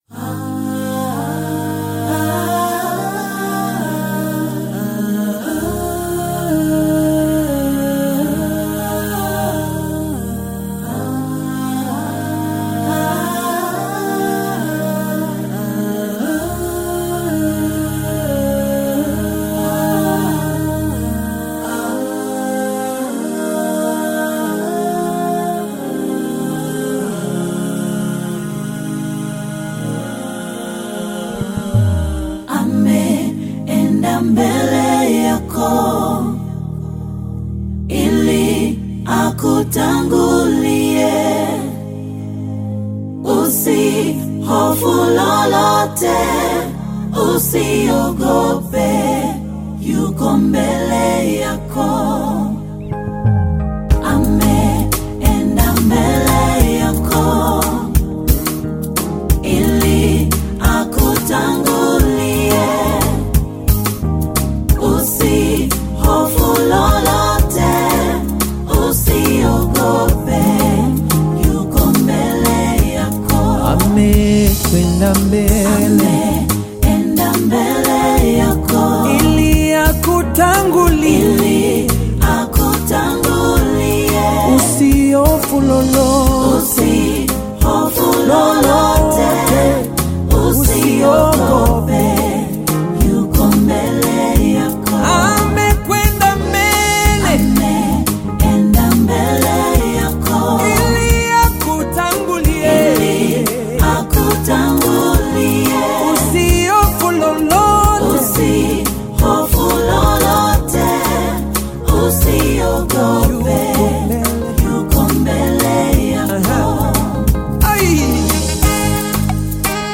Gospel music track
Bongo Flava
Gospel song